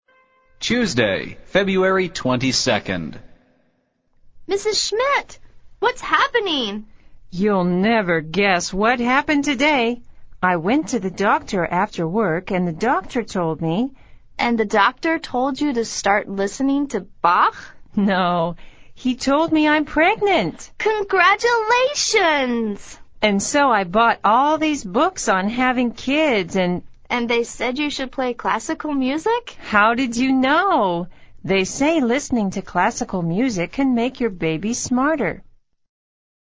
Sound of Bach music in the background.